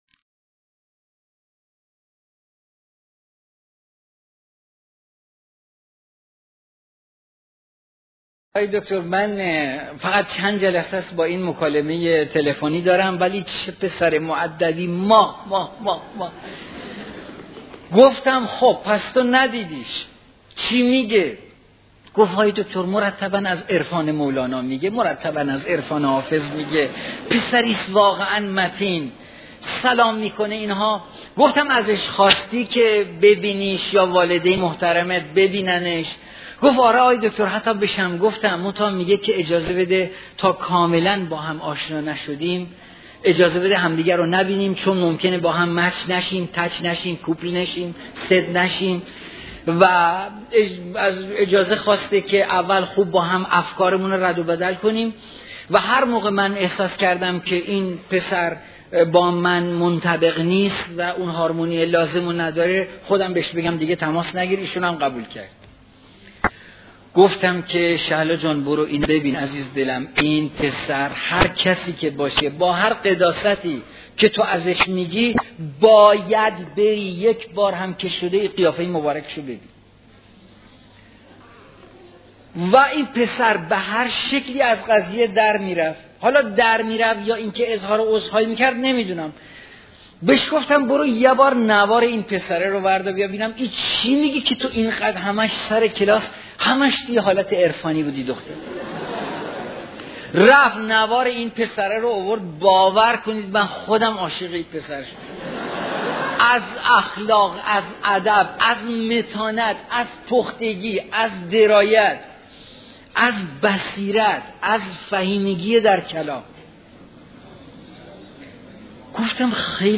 سخنرانی
در دانشگاه تهران